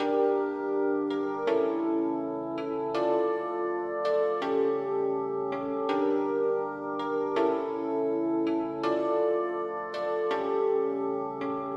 Tag: 163 bpm Trap Loops Bells Loops 1.98 MB wav Key : D